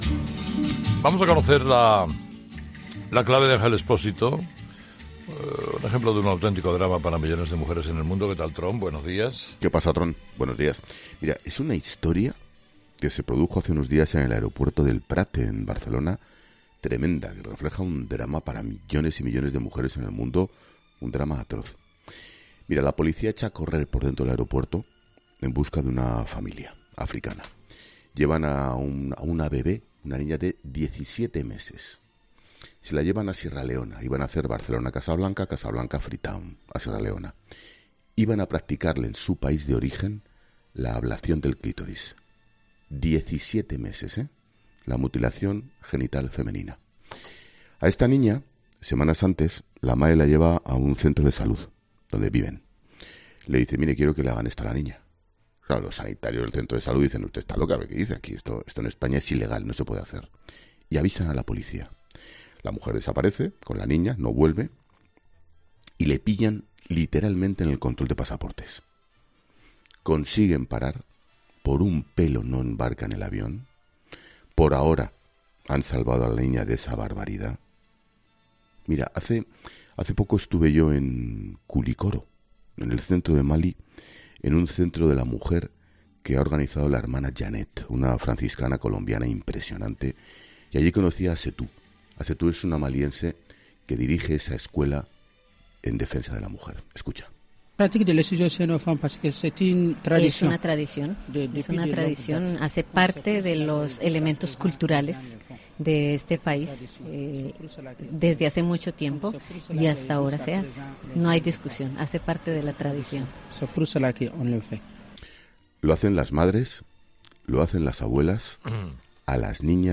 La clave de Ángel Expósito de este miércoles 15 de marzo en 'El Paseíllo de El Tron' en Herrera en COPE es un verdadero drama para millones de mujeres en el mundo.